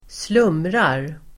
Ladda ner uttalet
slumra verb, doze, slumberGrammatikkommentar: A &Uttal: [²sl'um:rar] Böjningar: slumrade, slumrat, slumra, slumrarSynonymer: dåsa, snusa, sovaDefinition: sova lätt
slumrar.mp3